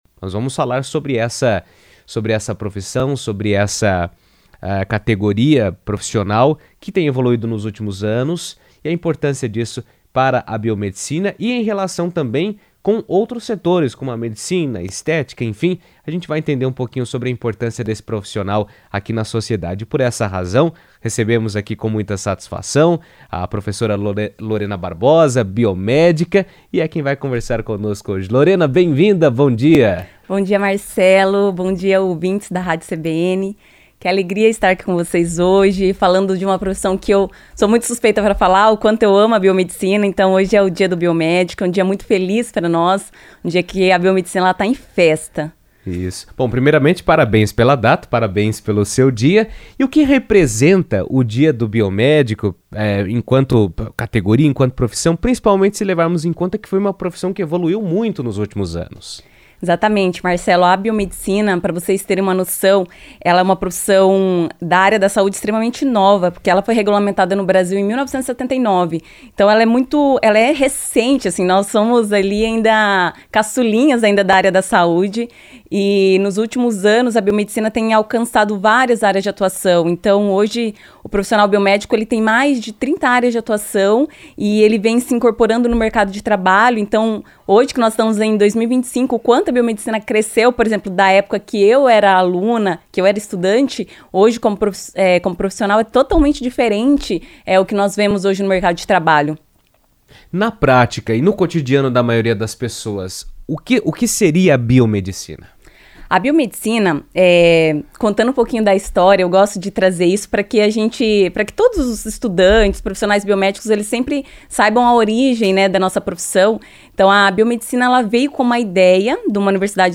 O Dia do Biomédico, celebrado nesta quinta-feira (20), destaca a expansão da profissão e a atuação em novas áreas da saúde e da estética. Além do crescimento no setor estético, biomédicos têm fortalecido parcerias com a medicina, participando de procedimentos complexos, como cirurgias cardiológicas e técnicas de fertilização in vitro. Em entrevista à CBN